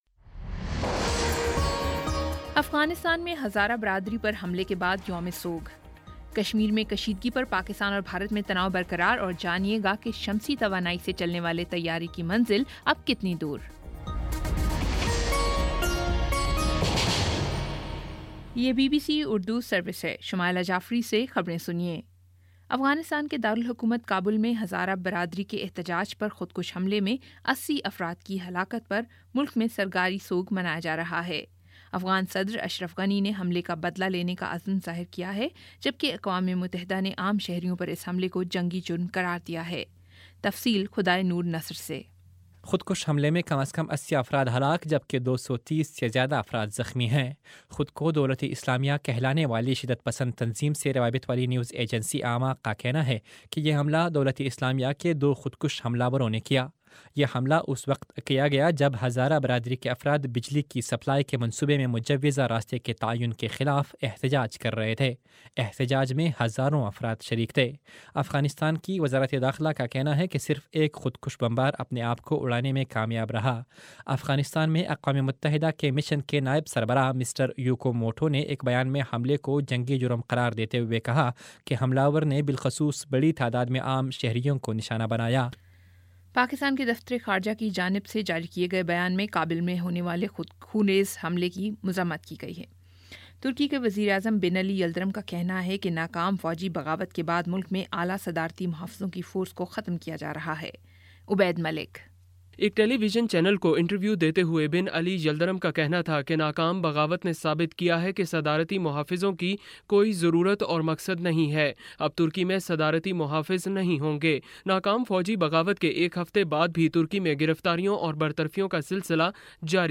جولائی 24 : شام پانچ بجے کا نیوز بُلیٹن